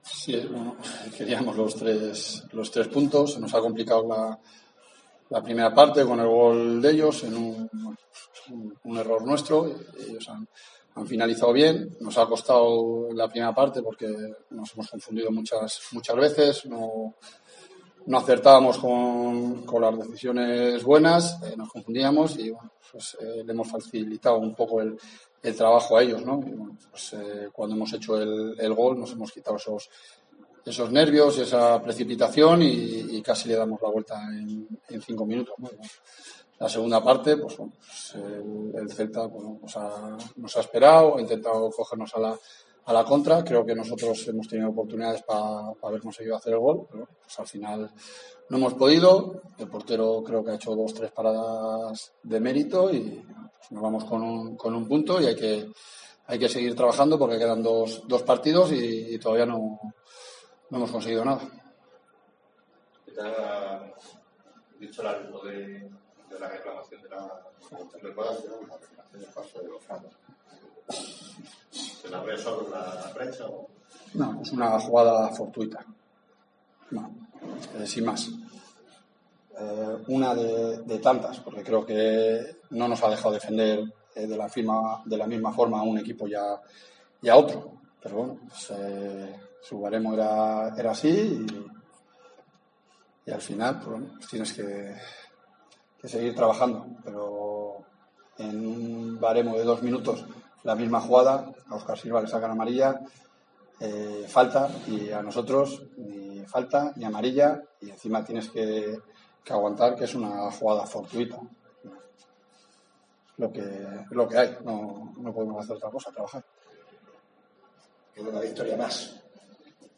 Escucha aquí las palabras del míster de la Deportiva tras el empate 1-1 en el campo del Celta B